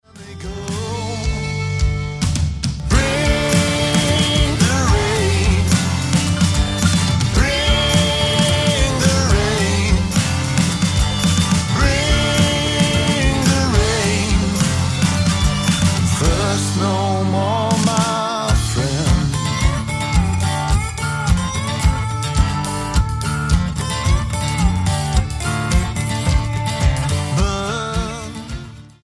Yorkshiremen playing Americana with a blues rock vibe
Style: Roots/Acoustic